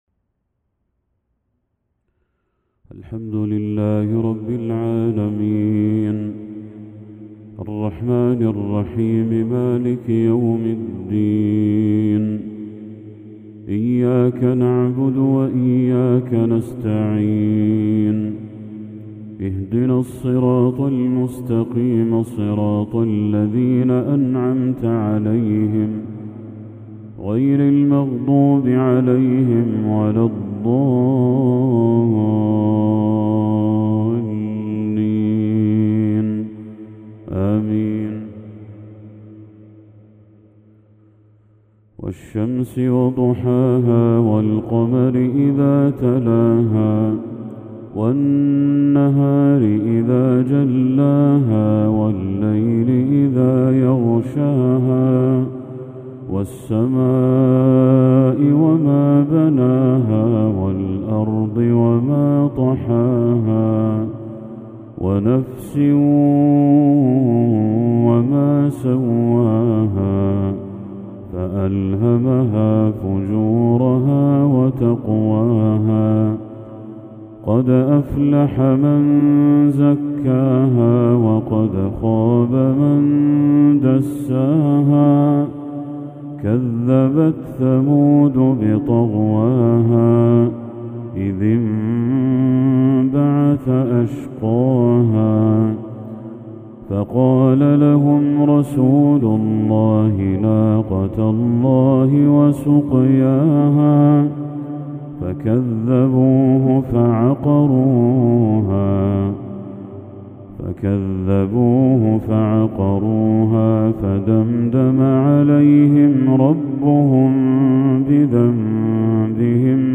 تلاوة جميلة للشيخ بدر التركي سورتي الشمس والضحى | مغرب 17 ذو الحجة 1445هـ > 1445هـ > تلاوات الشيخ بدر التركي > المزيد - تلاوات الحرمين